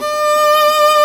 Index of /90_sSampleCDs/Roland L-CD702/VOL-1/STR_Viola Solo/STR_Vla2 % marc